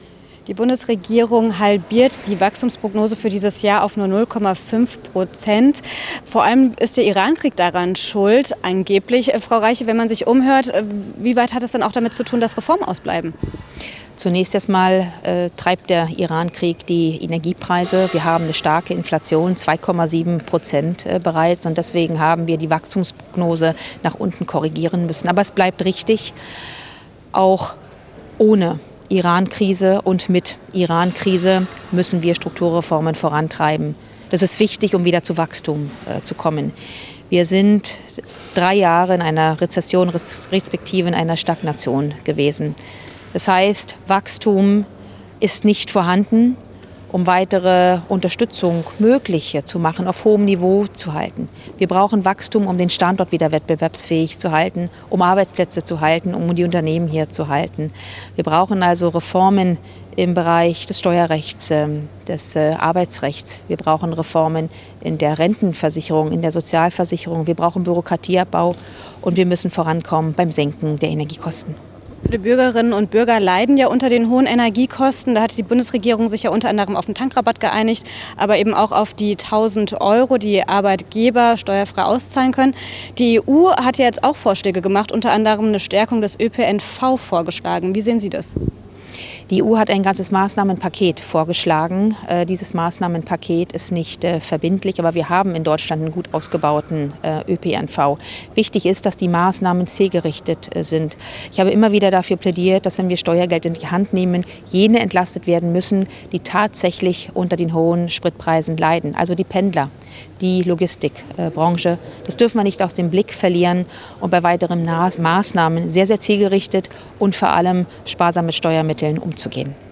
Wirtschaftsministerin Katherina Reiche erklärt im Interview, warum